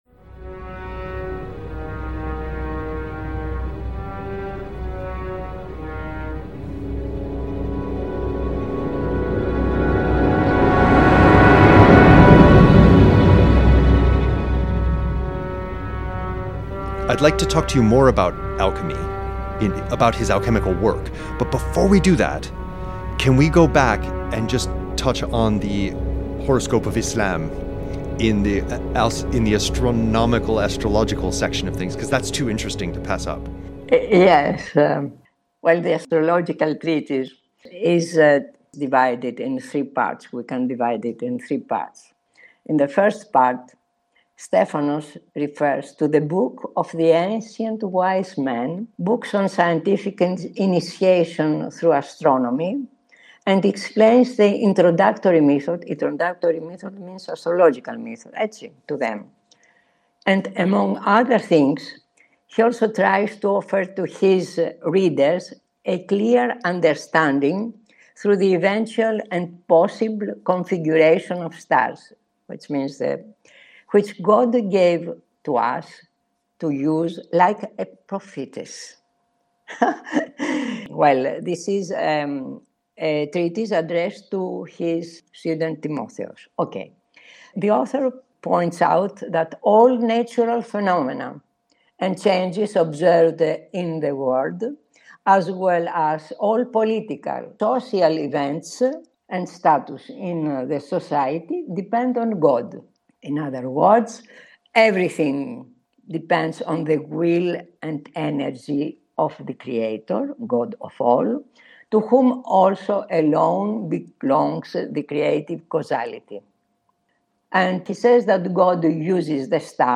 Interview Bio